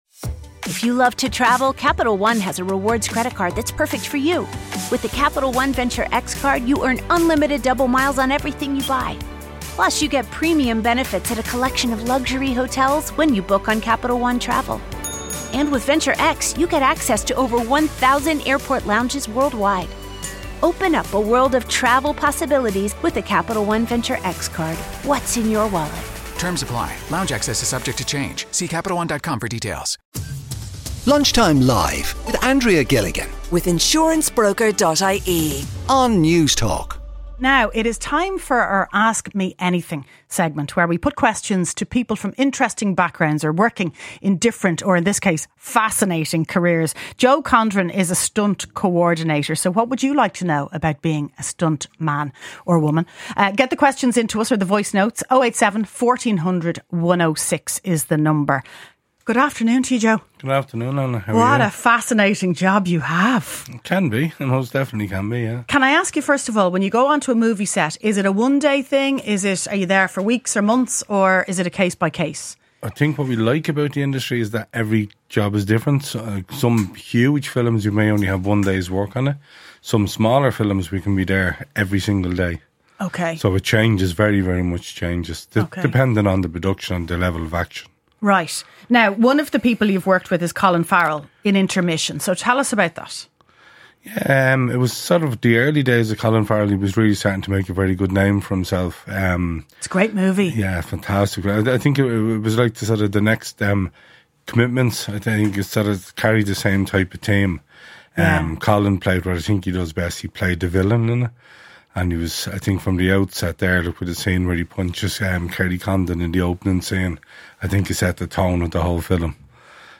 invites callers to have their say on the topics of the day
CONVERSATION THAT COUNTS | Ireland’s national independent talk station for news, sport, analysis and entertainment